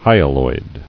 [hy·a·loid]